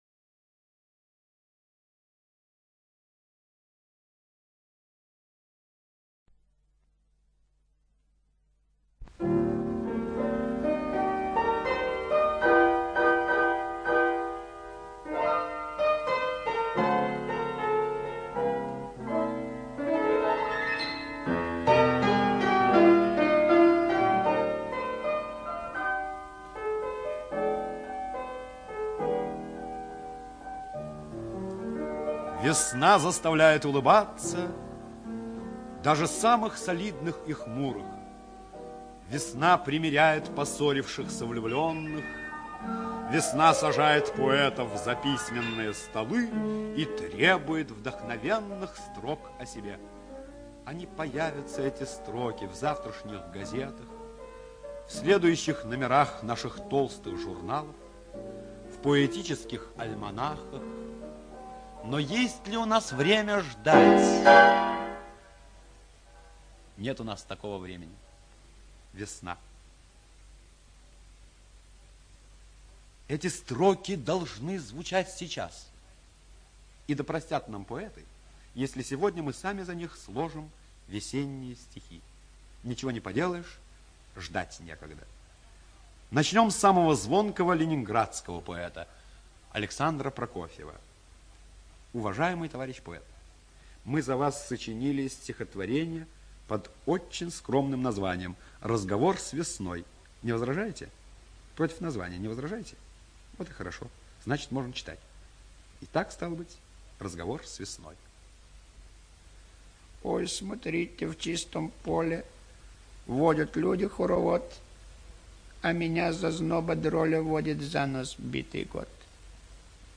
ЧитаетГердт З.